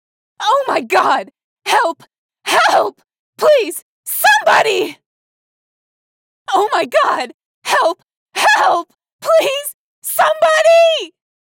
please_help_somebody.ogg